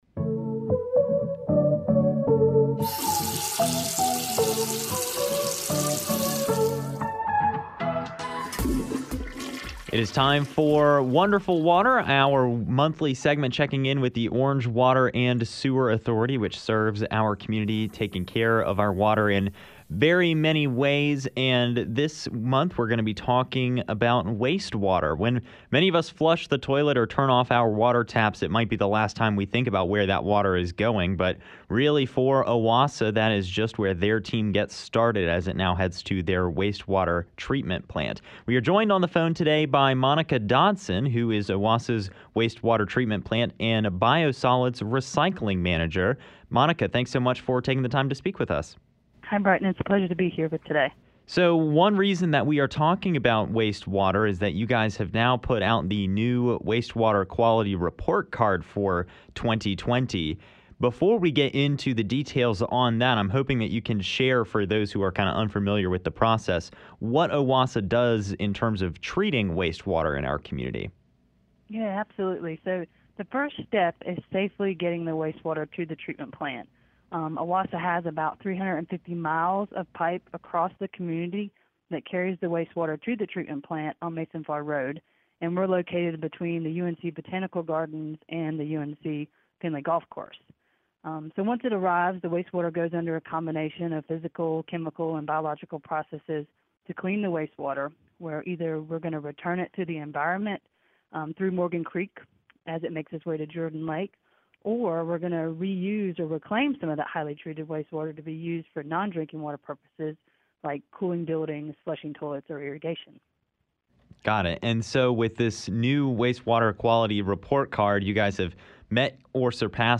Chapel Hill and Carrboro residents use roughly 7 million gallons of water a day, and “Wonderful Water” is a monthly conversation sponsored by the Orange Water and Sewer Authority highlighting its work to keep our community growing and water flowing.